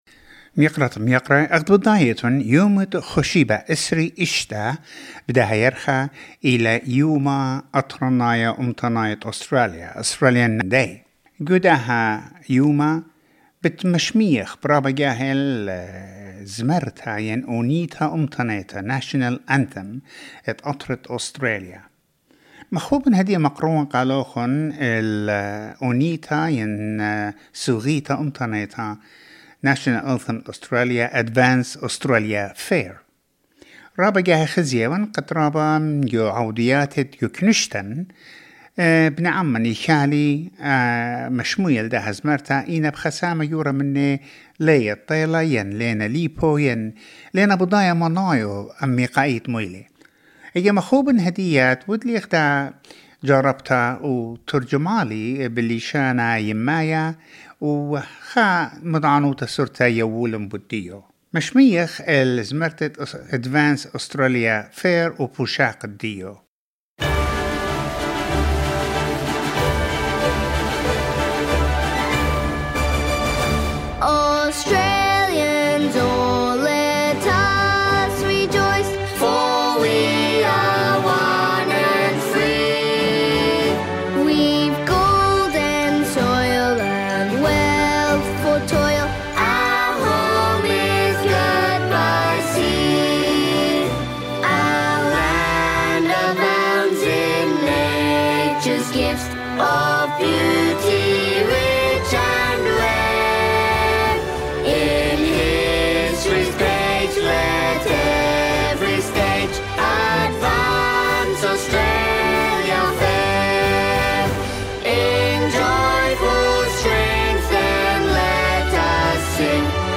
The Australian anthem read in Assyrian